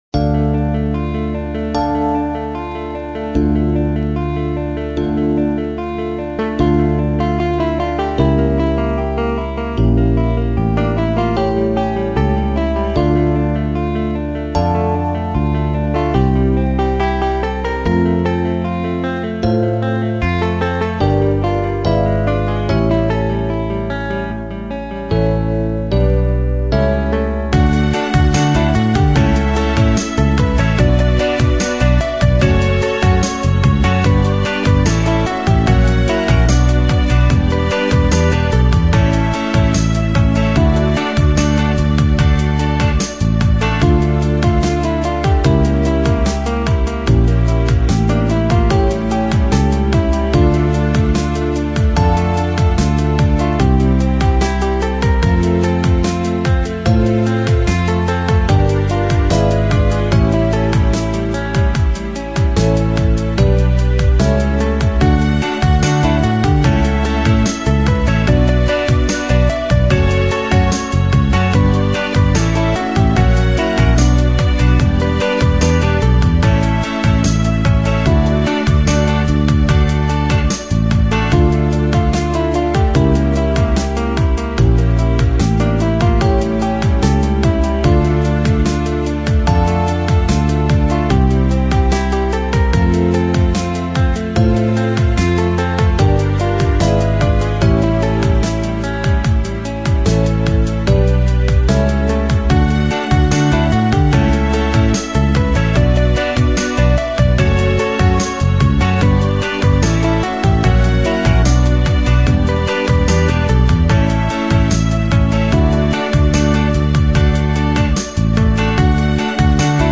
(минус)